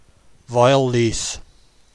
Foel means hill, Llus means bilberries. To hear how to pronounce Foel Lus, press play: